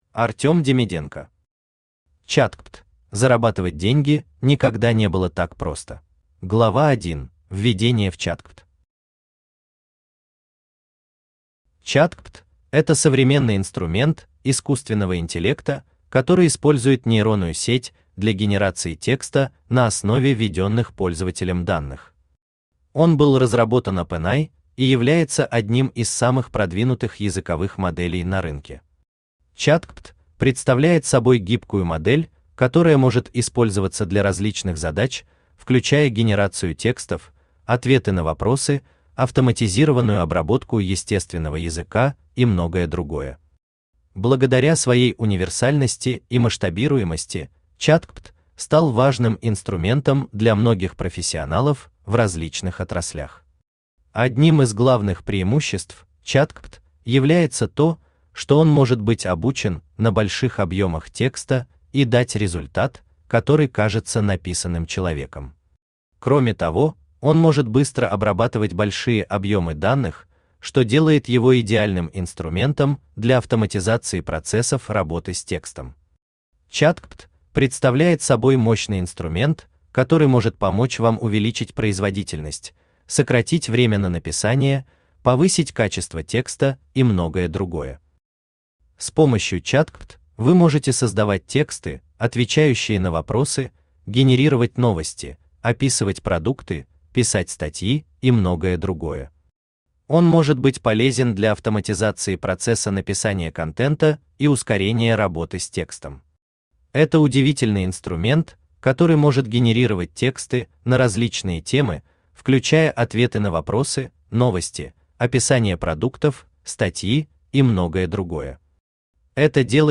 Аудиокнига ChatGPT.
Зарабатывать деньги никогда не было так просто Автор Артем Демиденко Читает аудиокнигу Авточтец ЛитРес.